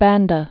(băndə, bän-)